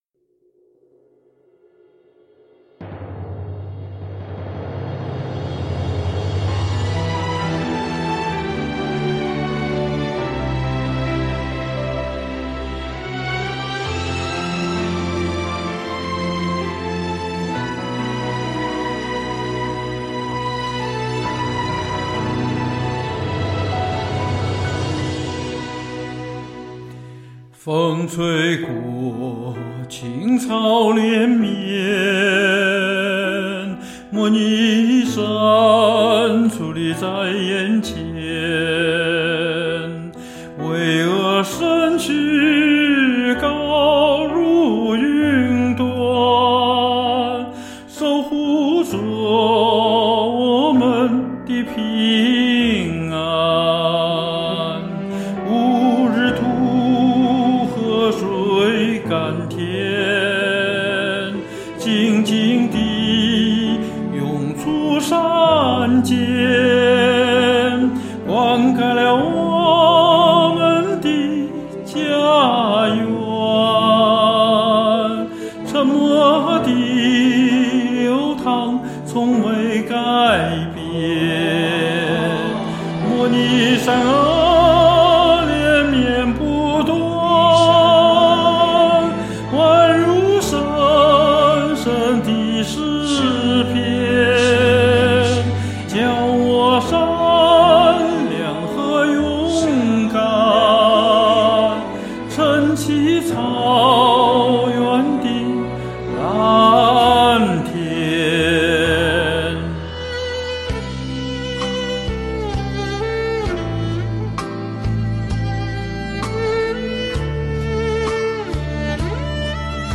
不过蒙语就免啦哈:)。